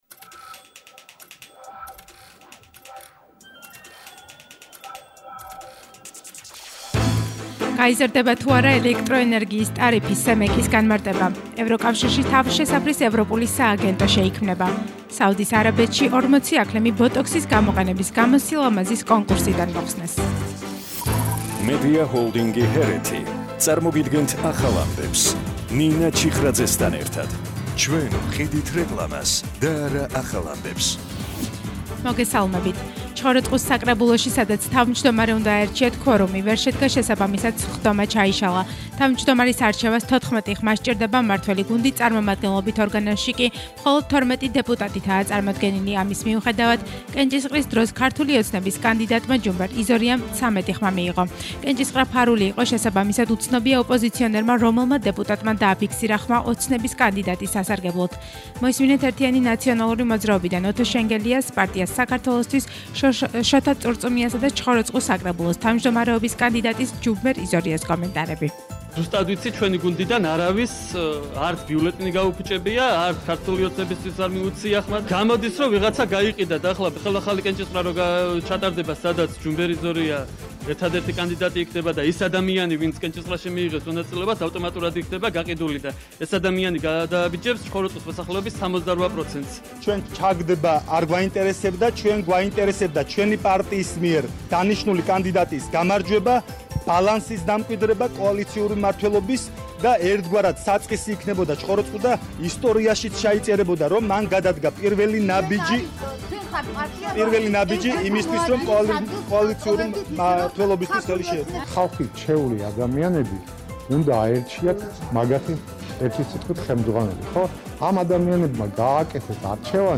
ახალი ამბები 17:00 საათზე – 09/12/21 - HeretiFM